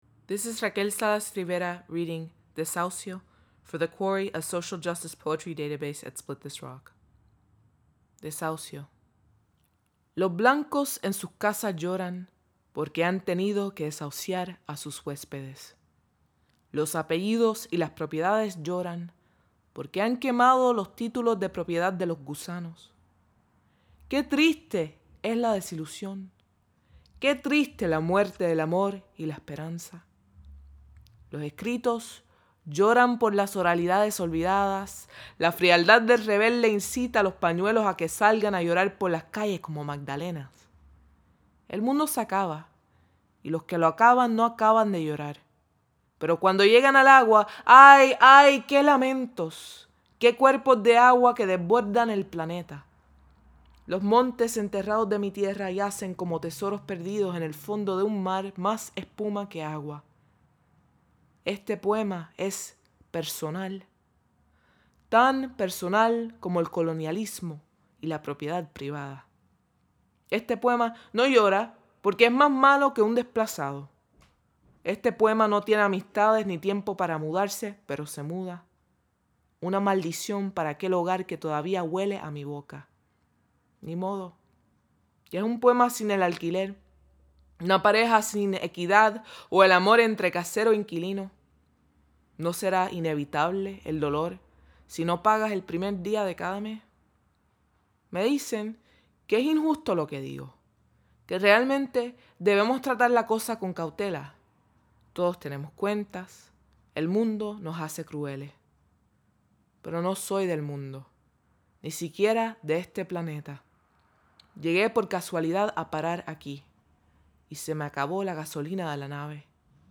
Listen as Raquel Salas Rivers reads "desahucio."